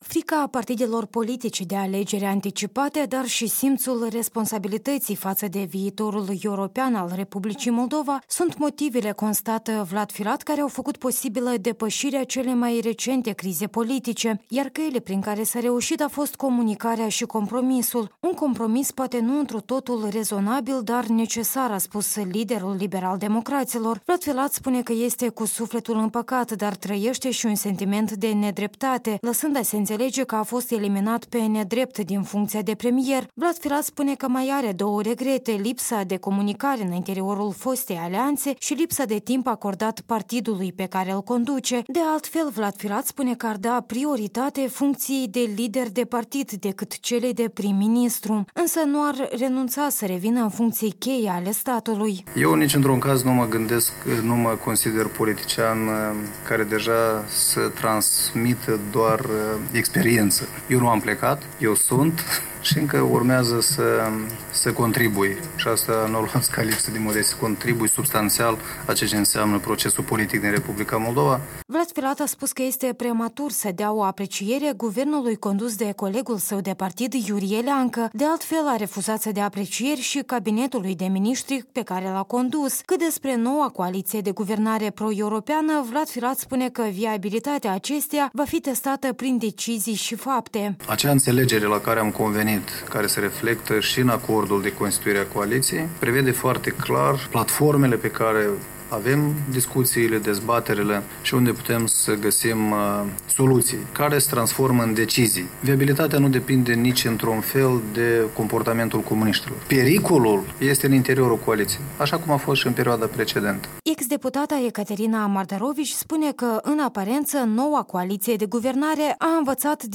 Fostul premier, liderul partidului liberal-democrat, la o „masă rotundă” cu câţiva analişti politici, despre învăţămintele pe care le-a tras din criza politică şi planurile sale de viitor.